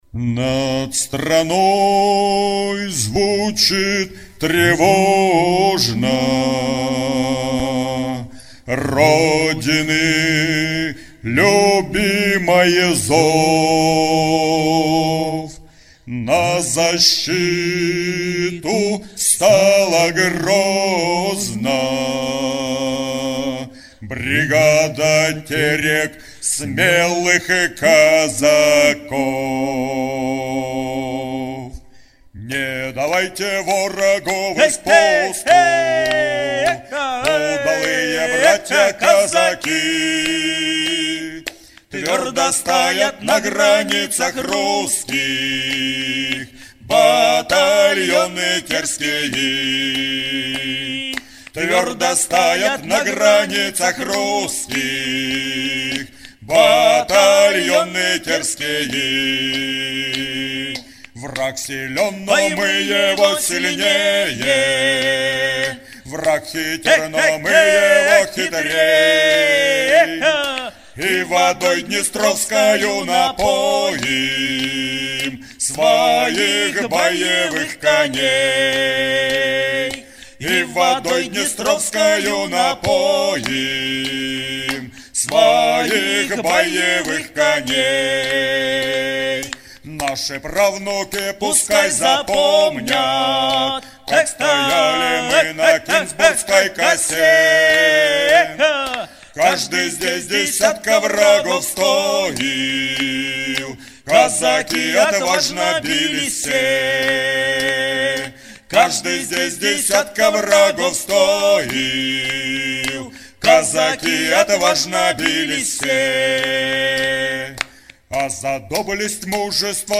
Песня исполнена дуэтом
Изначально песня была создана как старинная и протяжная, но, посоветовавшись с казаками, автор решил добавить ей ритма лезгинки.
Песня